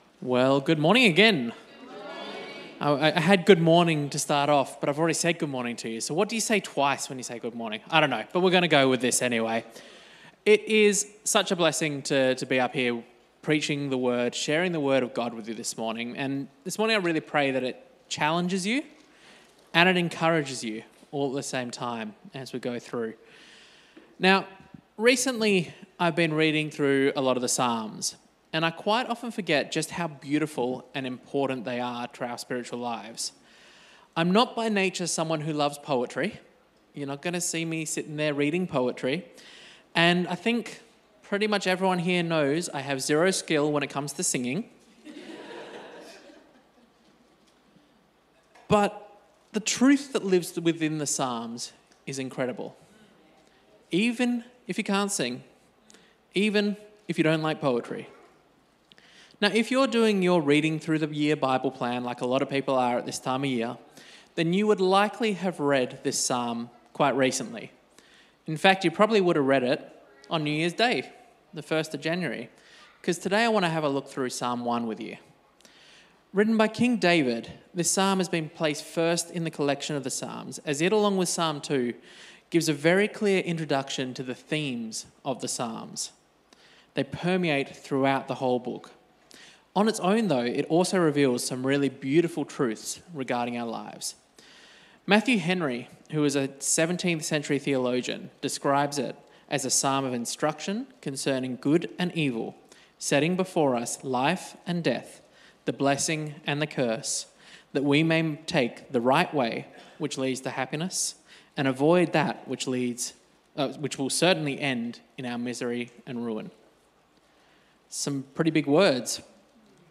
Sermon Text Good Morning Cityview Church.